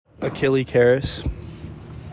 ⇓ Name Pronunciation ⇓